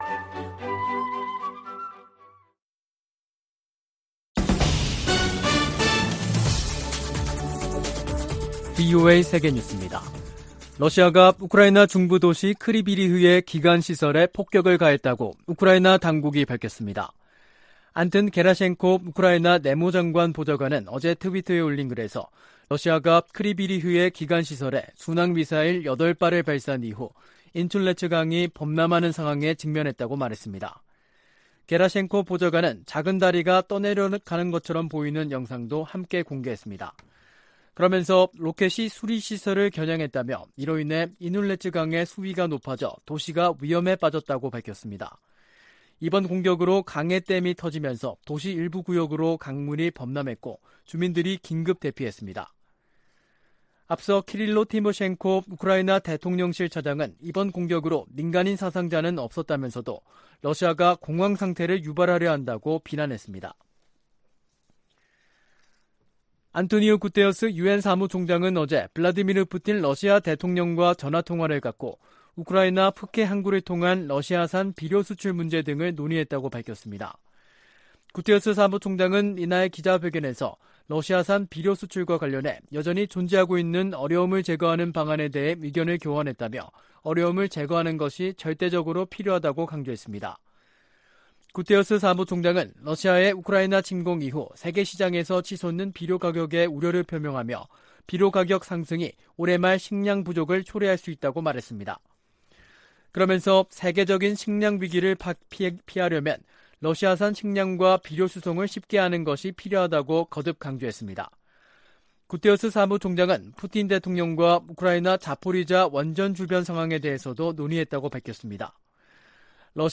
VOA 한국어 간판 뉴스 프로그램 '뉴스 투데이', 2022년 9월 15일 2부 방송입니다. 미 국방부는 북한의 핵무력 정책 법제화와 관련해 동맹의 안전 보장을 위해 모든 조치를 취할 것이라고 밝혔습니다. 미 국제기구대표부는 북한의 핵무력 법제화에 우려를 표명하며 북한은 결코 핵무기 보유국 지위를 얻을 수 없을 것이라고 강조했습니다. 미 상원의원들이 대북 압박과 억지를 유지하고 대북특별대표직을 신설할 것 등을 요구하는 법안을 발의했습니다.